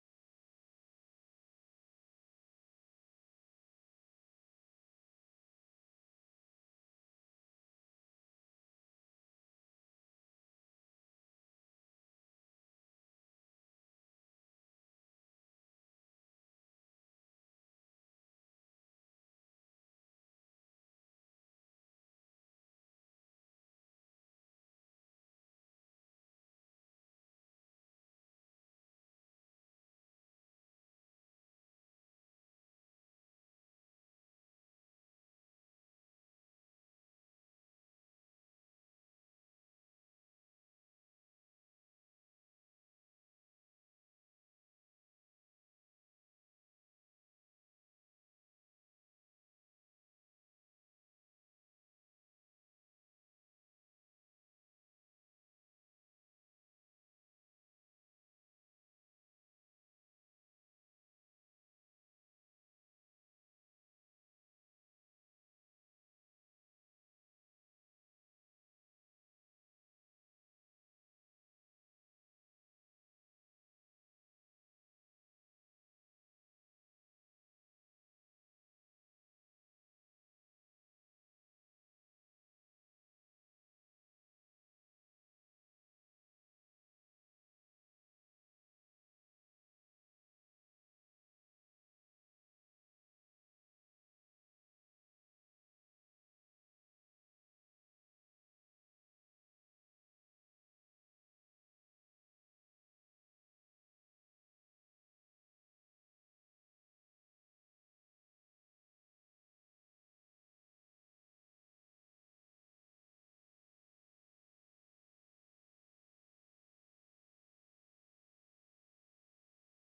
Our very talented worship team leads us in some beautiful songs about Grace.